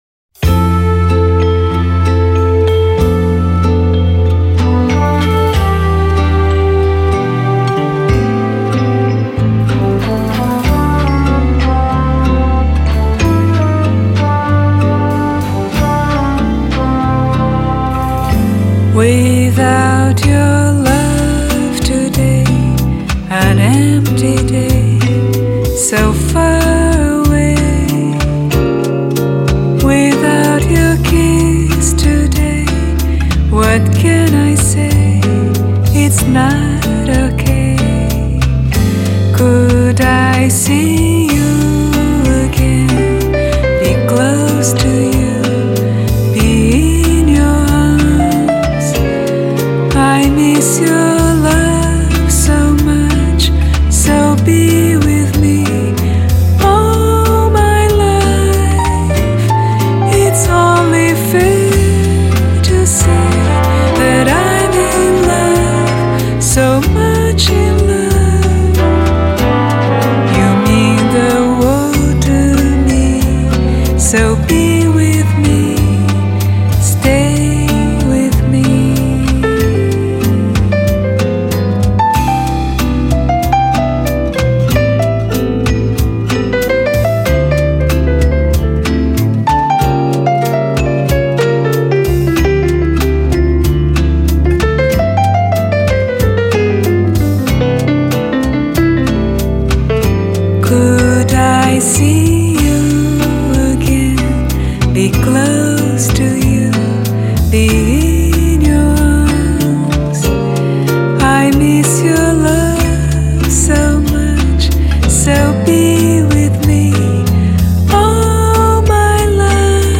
音樂類型 : 爵士樂  Bossa Nova[center]